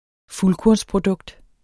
Udtale [ ˈfulkoɐ̯ns- ]